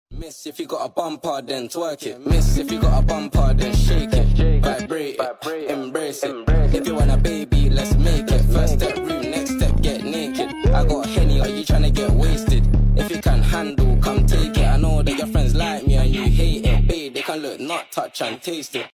Basketball sound effects free download